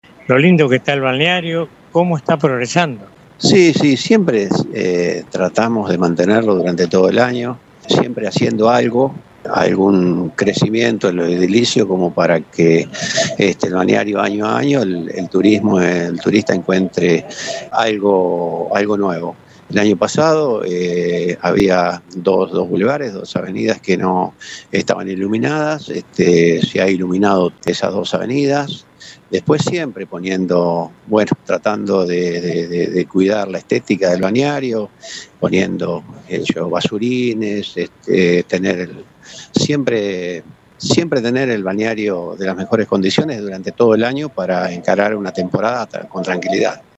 En un dialogo con LU24, Jorge Haag, delegado municipal del Balneario San Cayetano, trazó un balance sumamente positivo de la actualidad del destino, destacando la transformación que ha vivido la villa balnearia gracias a inversiones estratégicas y una planificación urbana rigurosa.